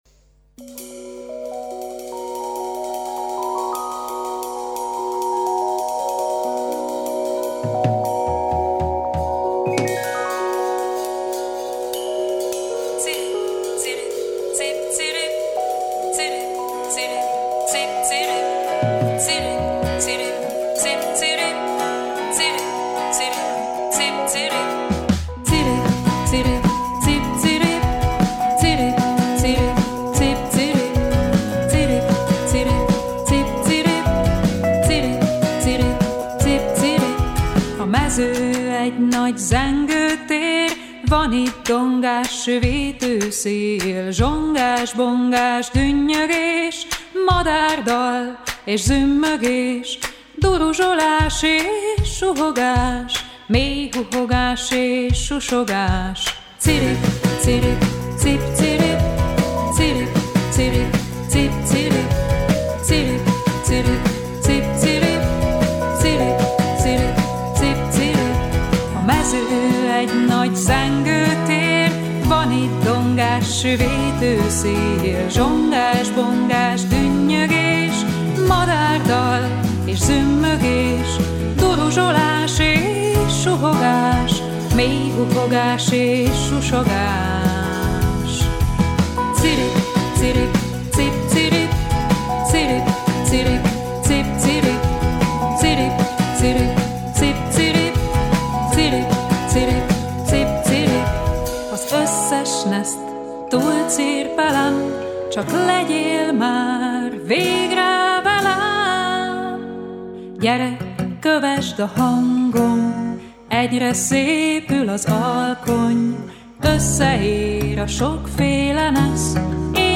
Ő volt a vendégünk a Hangolóban.